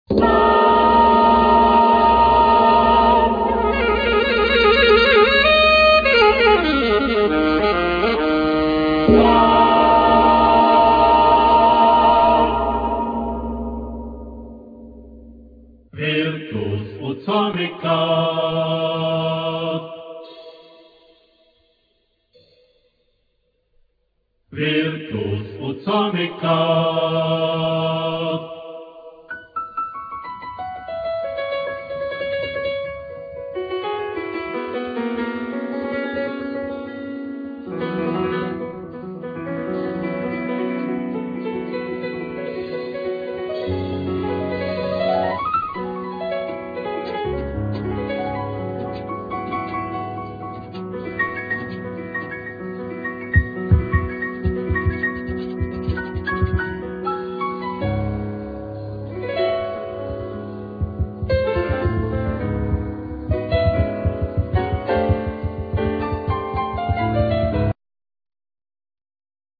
Flute,Alt flute,Alt sax, Marimba, Drums, Percussion, etc
Piano, El.piano, Cembalo
Chorus
Strings Quartet(1st Violin, 2nd Violin, Viola, Cello)